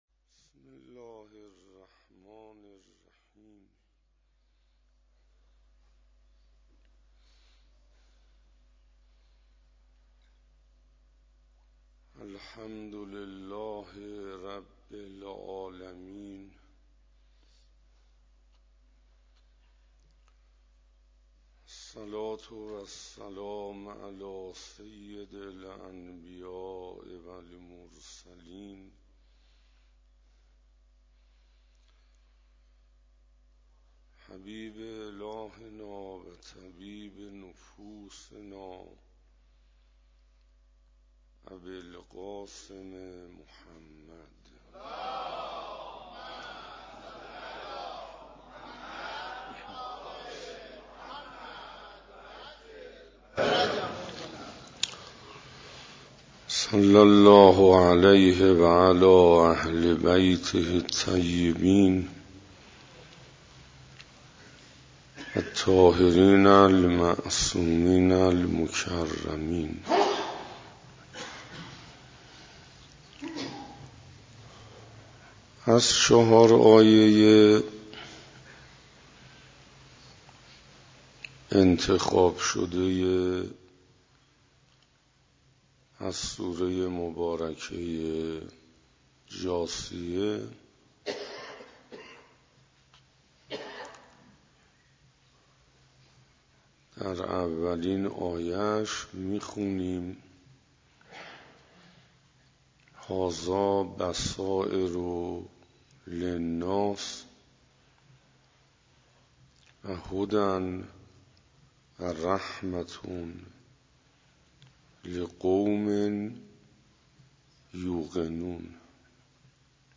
دهه اول شعبان97 - حسینیه هدایت - جلسه هشتم - مسولیت پیامبر